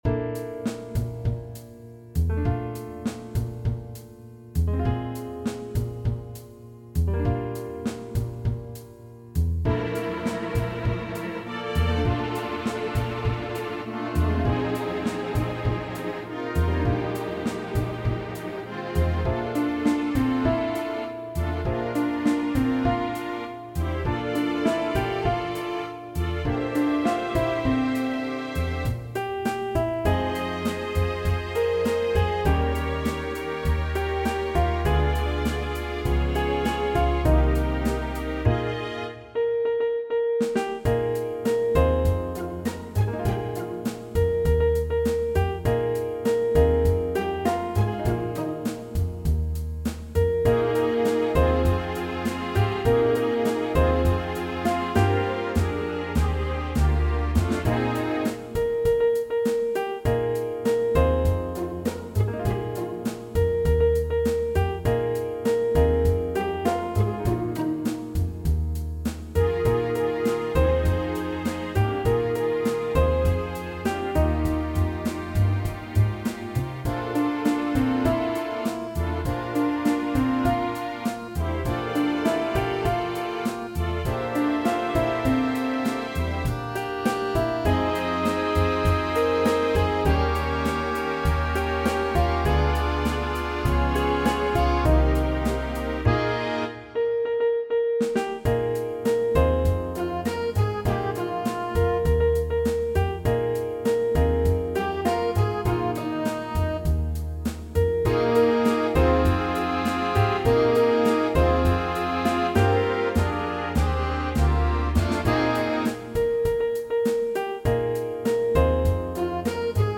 All audio files are computer-generated.